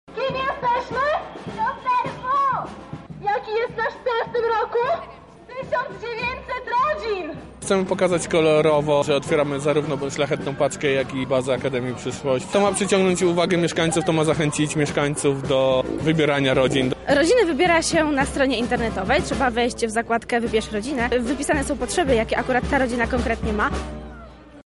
Więcej na ten temat mówią organizatorzy:
Organizatorzy